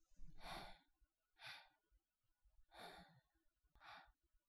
YX呼吸1.wav 0:00.00 0:04.50 YX呼吸1.wav WAV · 388 KB · 單聲道 (1ch) 下载文件 本站所有音效均采用 CC0 授权 ，可免费用于商业与个人项目，无需署名。